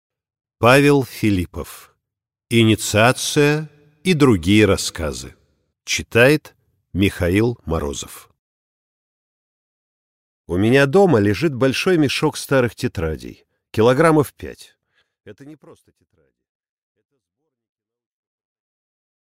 Аудиокнига Инициация и другие рассказы | Библиотека аудиокниг
Прослушать и бесплатно скачать фрагмент аудиокниги